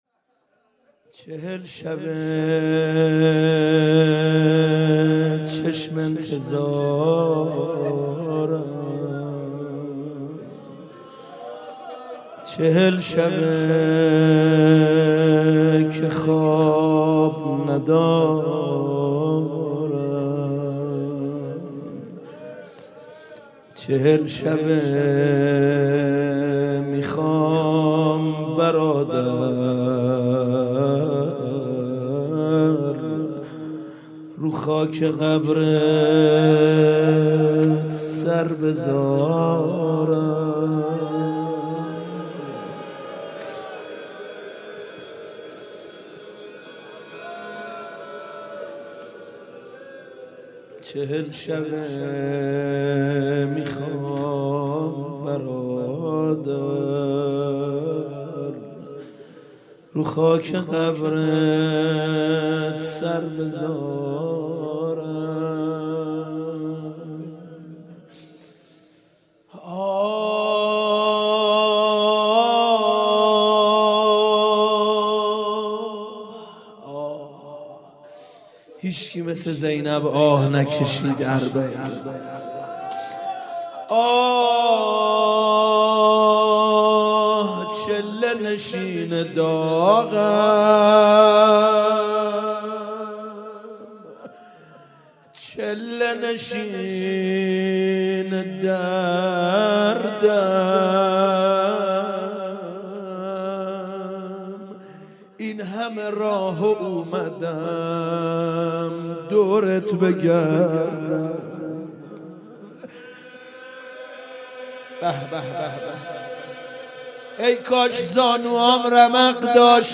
هیئت آل یاسین - روضه - چهل شب چشم انتظارم